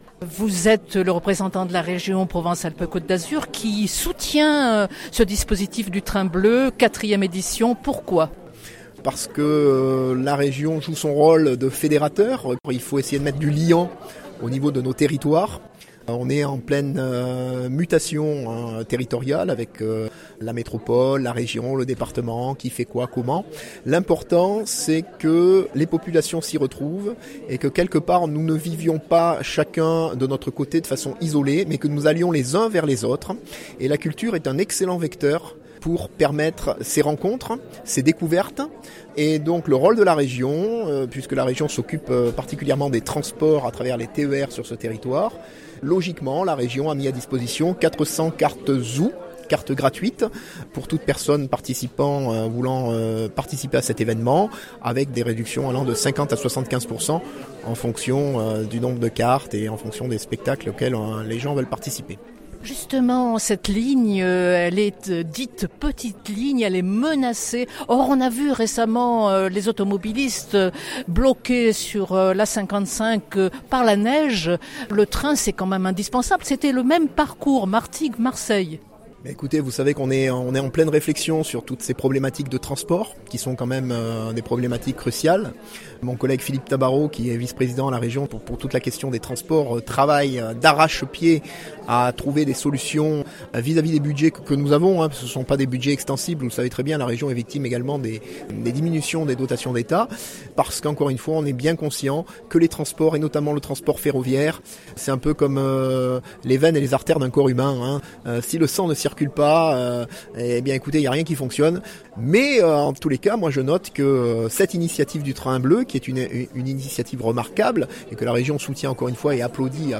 On marche, on prend le bus, le bateau, le TER de la Région qui offre 400 cartes ZOU son_copie_petit-223.jpgPhilippe Maurizot, élu de l’Ouest de l’Étang de Berre et conseiller régional se réjouit de cette manifestation artistique qui, pour la première fois, va au Nord de l’Étang de Berre et permet «de mettre du liant entre les territoires»…